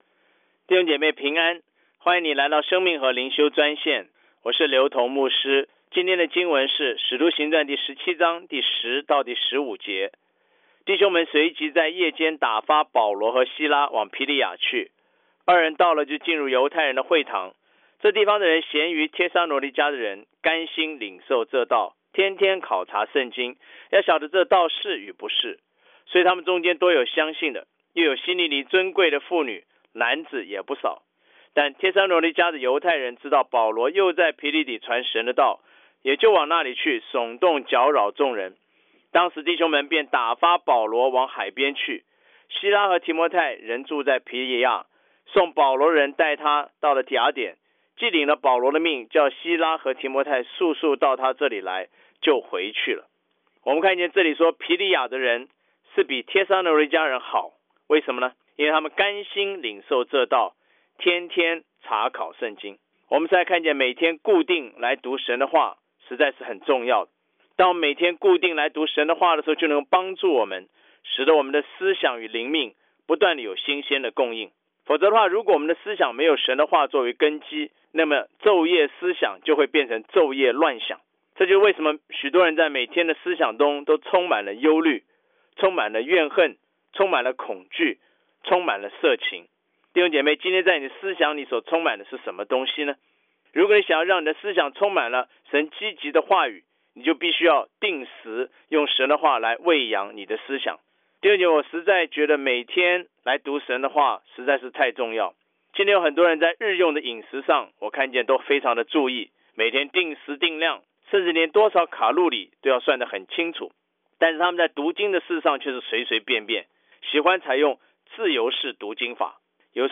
藉着每天五分钟电话分享，以生活化的口吻带领信徒逐章逐节读经